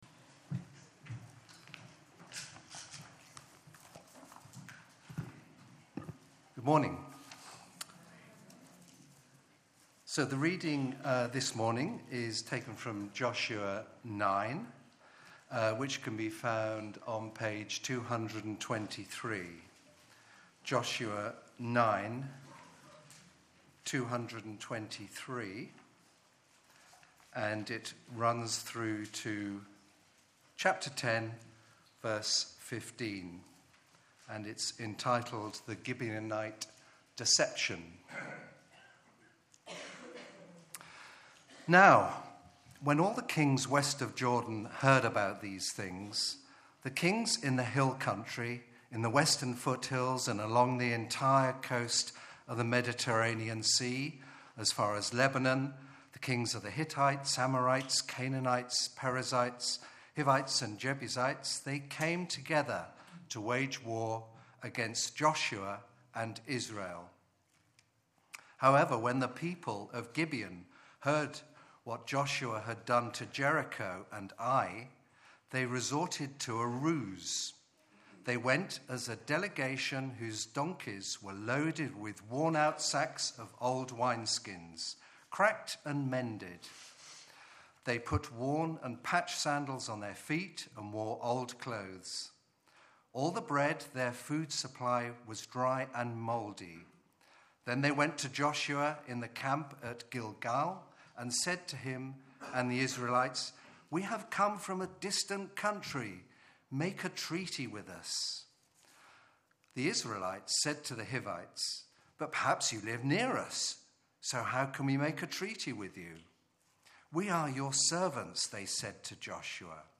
Sermons | ChristChurch Banstead